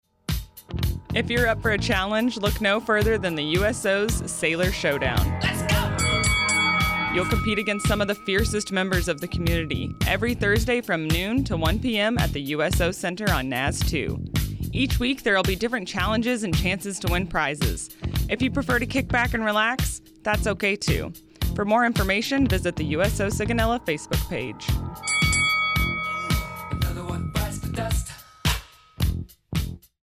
NAVAL AIR STATION SIGONELLA, Italy (June 21, 2023) A radio spot describing USO Sigonella's weekly Sailor Showdown event.